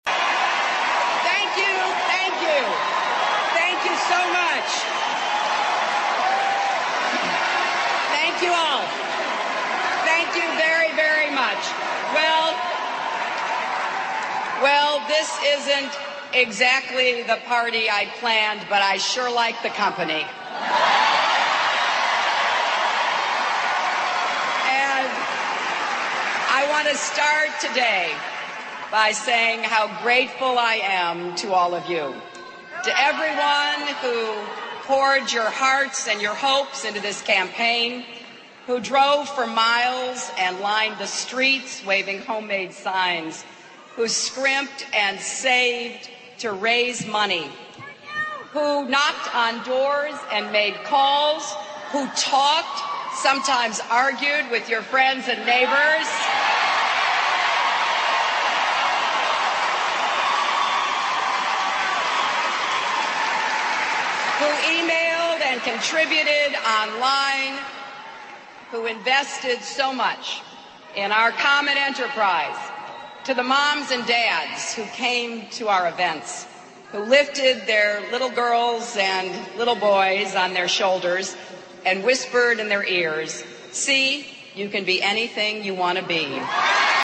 名人励志英语演讲 第67期:我放弃了 但我会继续战斗(1) 听力文件下载—在线英语听力室